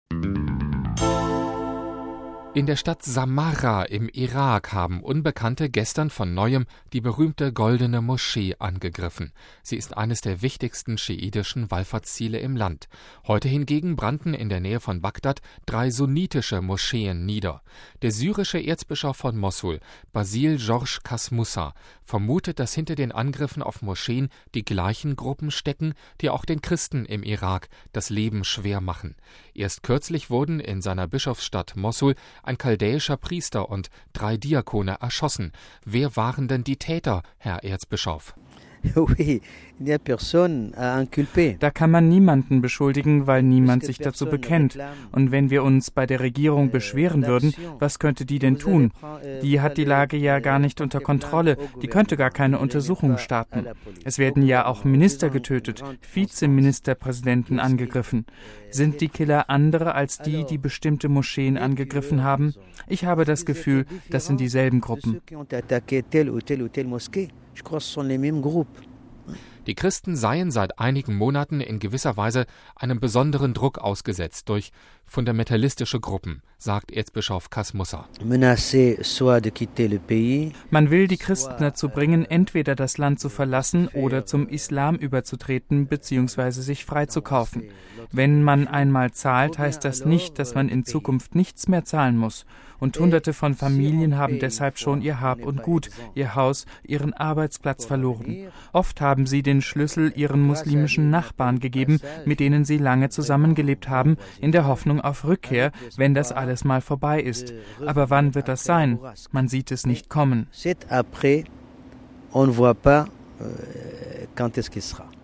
Wer waren die Täter, Herr Erzbischof?